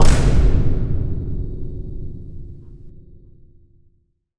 We started with five different sources, including a recording made in an abandoned warehouse with significant natural reverb.
We combined several metallic impacts, some of which were transposed to higher pitches, with a resonant metal sound filtered to retain only the lower part of the spectrum. The idea was to concentrate the energy on the low end, all while maintaining a sharp attack.
We then equalized the whole thing, heavily compressed the signal, and added a limiter.
The result is a dense, resonant, industrial-style metallic impact.
If you use a compressed copy found elsewhere on the internet, you’ll hear the difference right away: the file found here is much cleaner than the versions that can be found elsewhere.
Bluezone-Cimpact-sound-001.wav